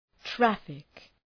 Προφορά
{‘træfık}